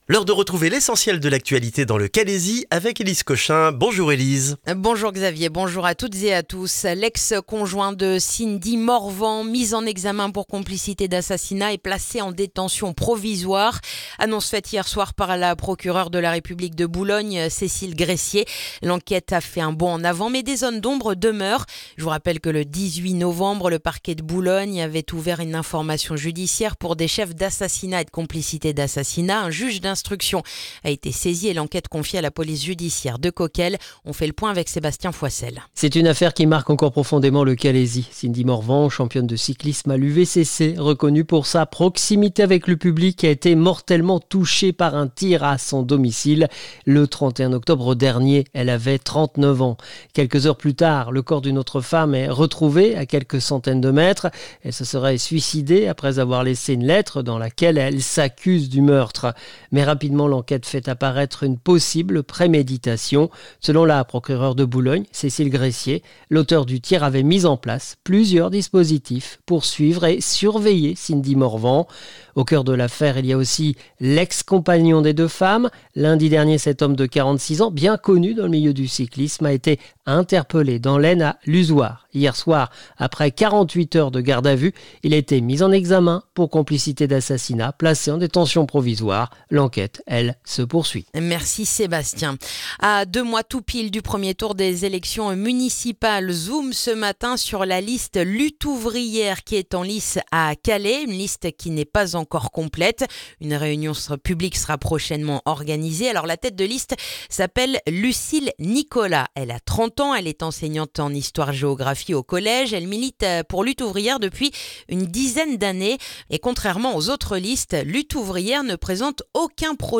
Le journal du jeudi 15 janvier dans le calaisis